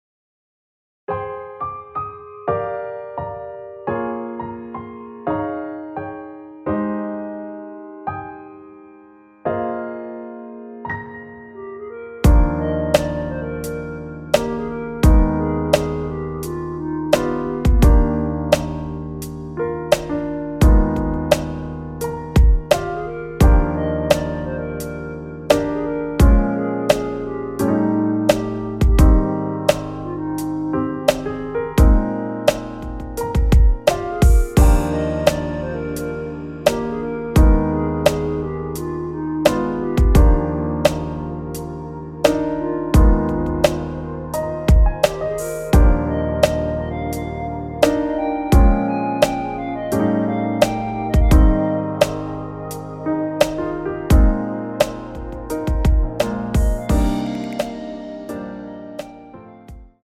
원키 멜로디 포함된 MR입니다.(미리듣기 확인)
Eb
멜로디 MR이라고 합니다.
앞부분30초, 뒷부분30초씩 편집해서 올려 드리고 있습니다.
중간에 음이 끈어지고 다시 나오는 이유는